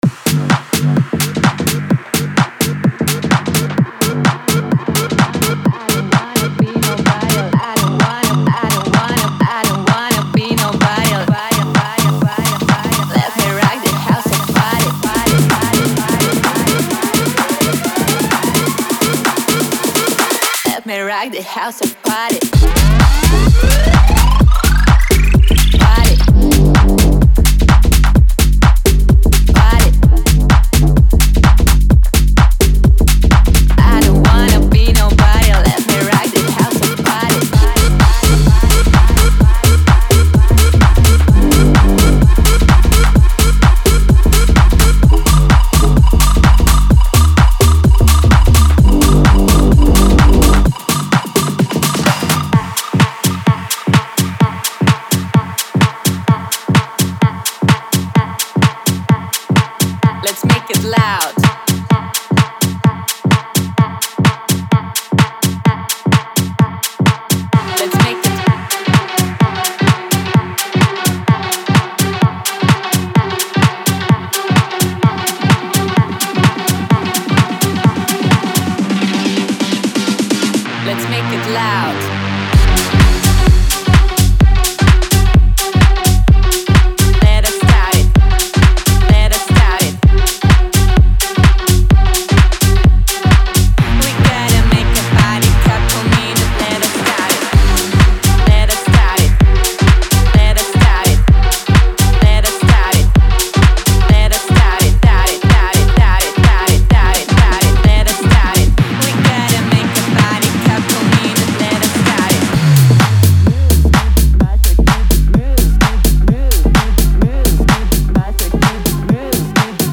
デモサウンドはコチラ↓
Genre:Tech House
124, 125, 126, 127, 128 BPM
55 Bass Loops
61 Synth Loops
40 Vocals  (20 Dry, 20 Wet)